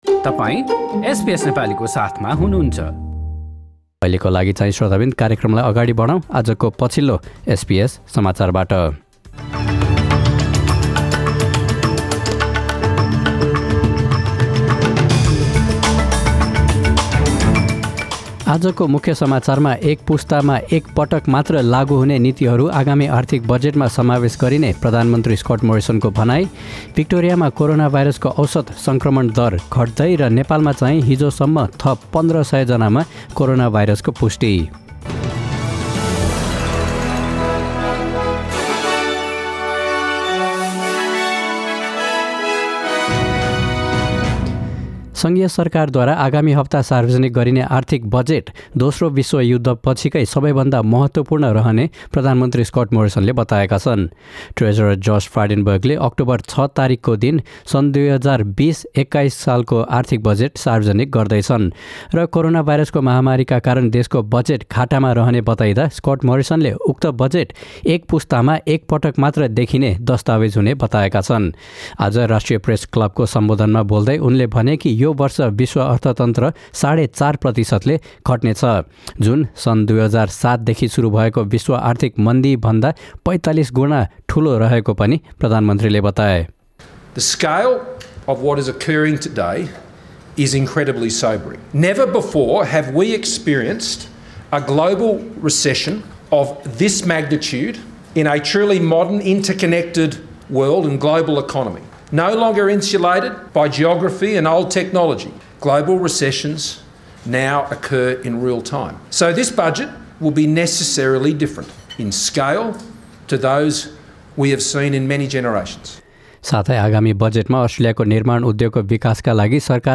Listen to the latest news headlines in Australia from SBS Nepali radio.